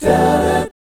1-EMI7.wav